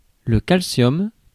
Ääntäminen
Ääntäminen Tuntematon aksentti: IPA: /kal.sjɔm/ Haettu sana löytyi näillä lähdekielillä: ranska Käännös Konteksti Ääninäyte Substantiivit 1. calcium kemia US Suku: m .